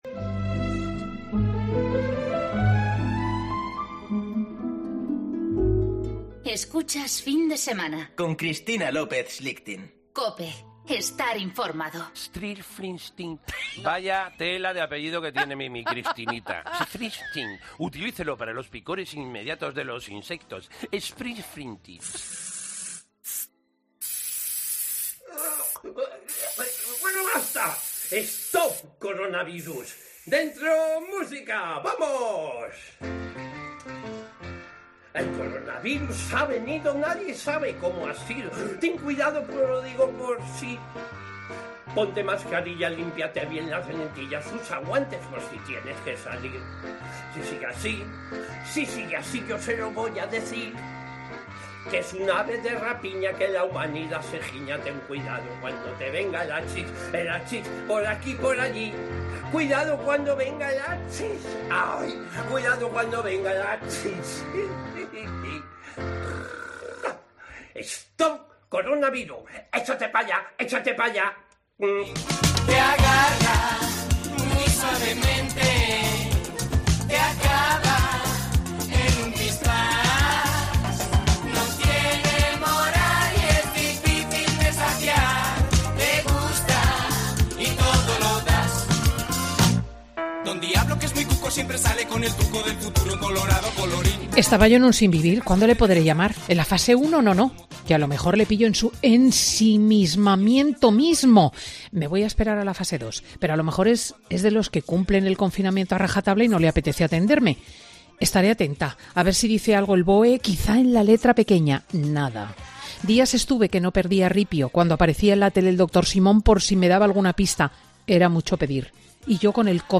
El humorista ha pasado por Fin de Semana para hacernos reír un rato y recordarnos que sigue su carrera en redes sociales